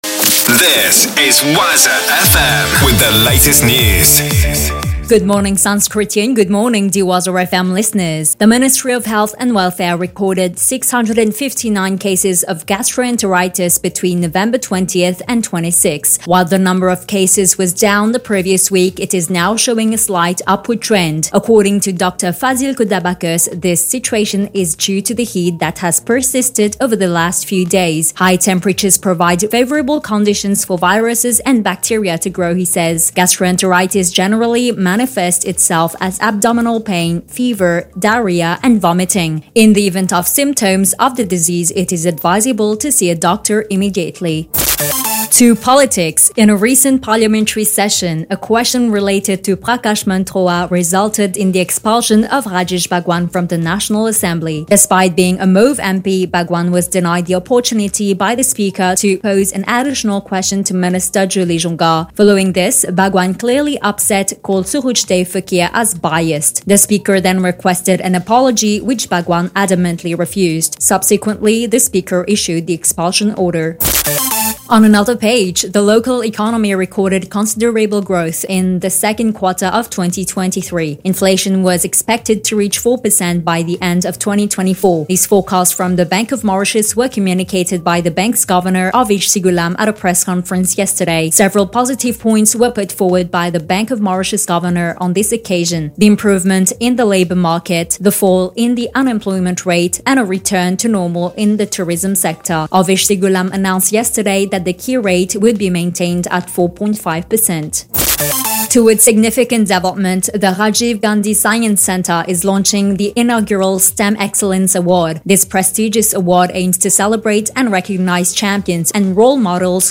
NEWS 9H - 29.11.23